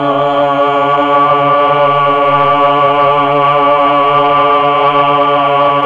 Index of /90_sSampleCDs/Roland LCDP09 Keys of the 60s and 70s 1/VOX_Melotron Vox/VOX_Tron Choir